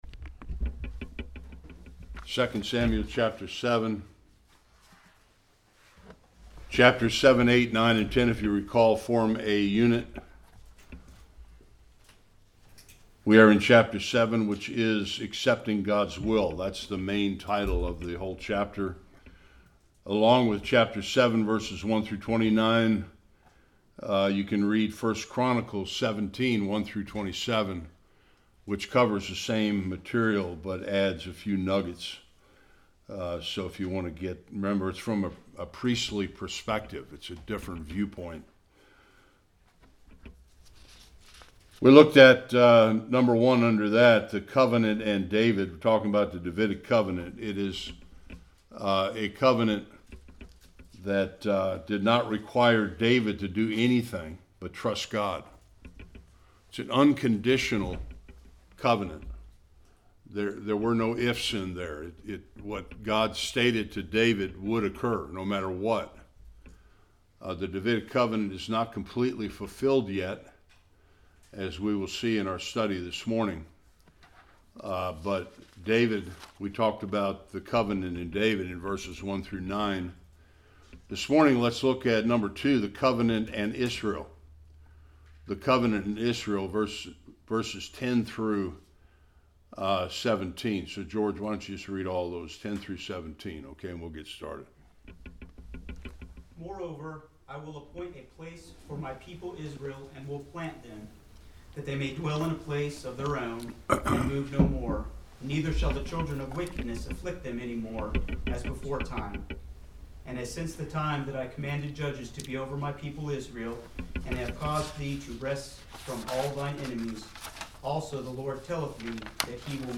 10-17 Service Type: Sunday School What the Davidic Covenant meant for Israel.